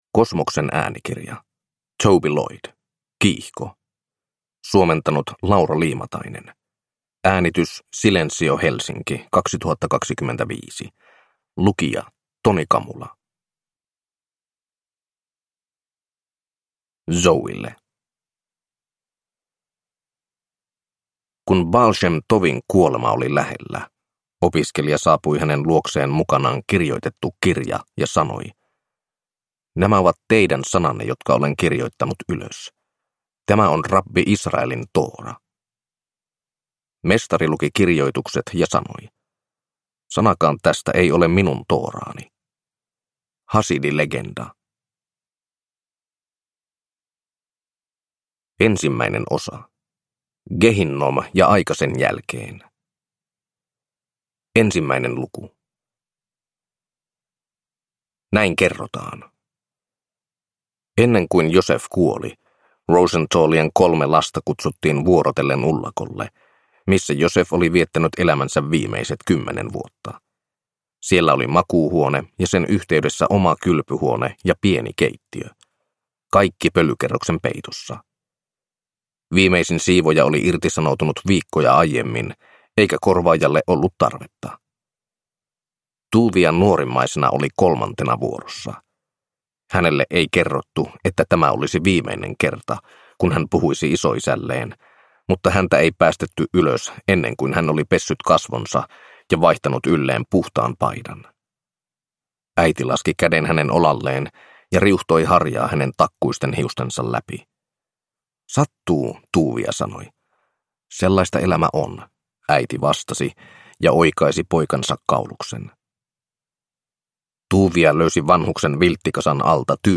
Kiihko – Ljudbok